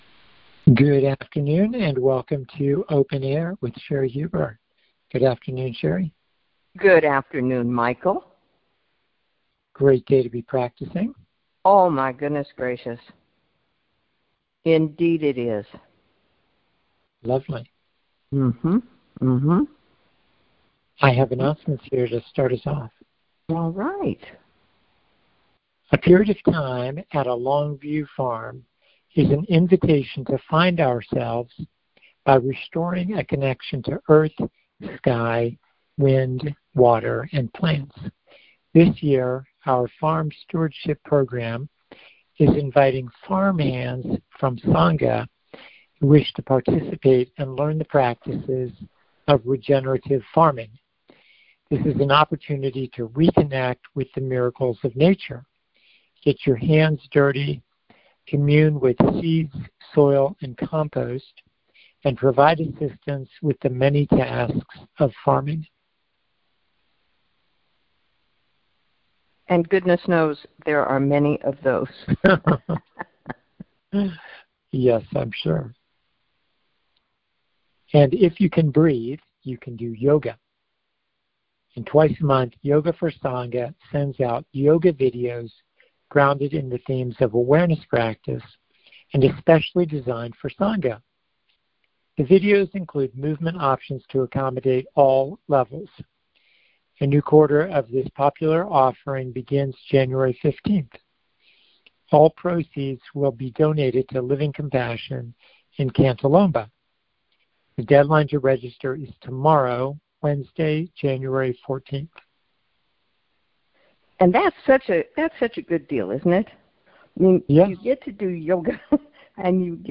are on the air to facilitate a conversation to support the practice of conscious, compassionate awareness.